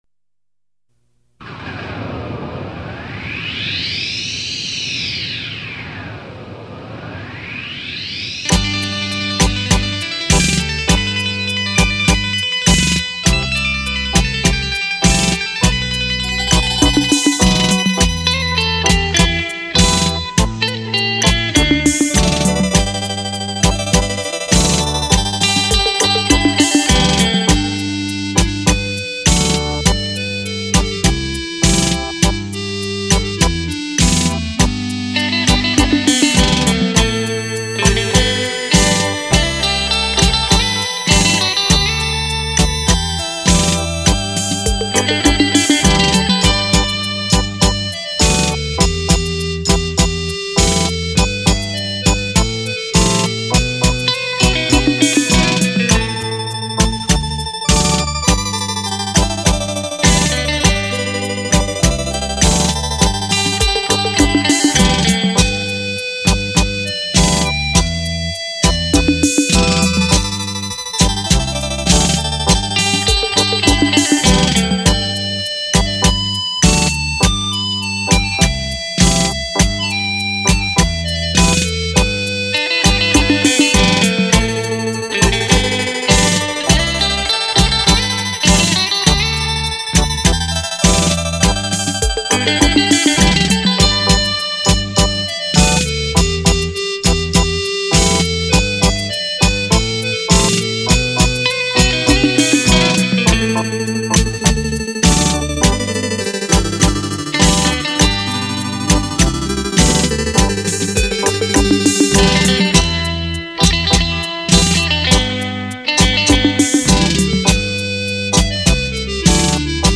强音震全场 狂野三千响
HiFi超强立体音响 魔鬼版
360°环绕全场 小心你的喇叭！！